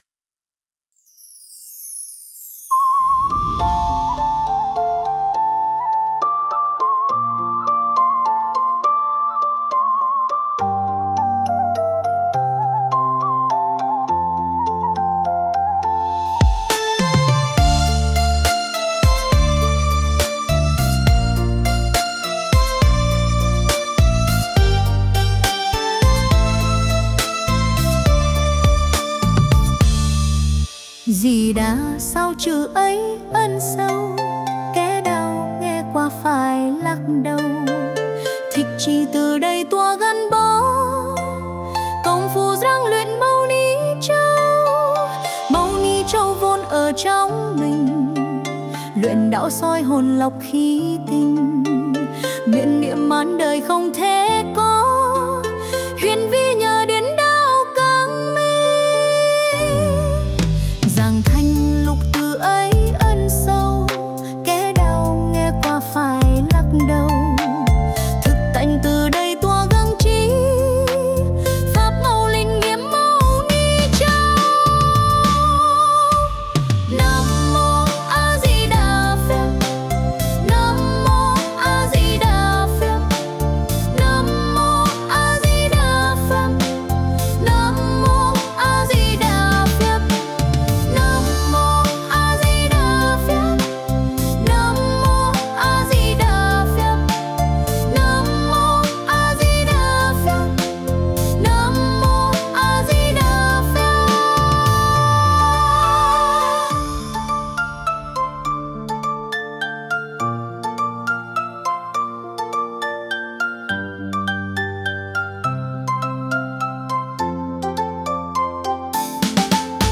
NHẠC THƠ